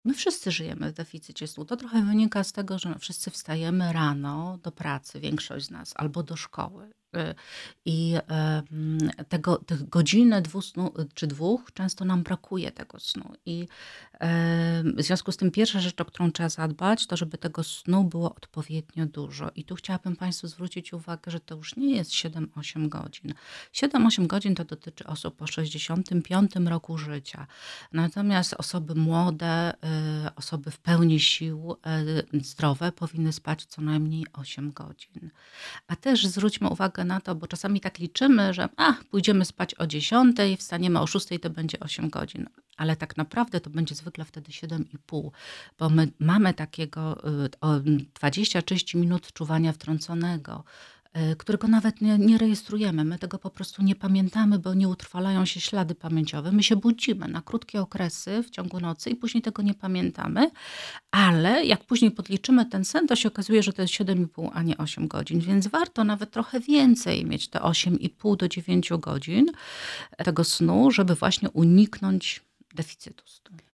W studiu Radia Rodzina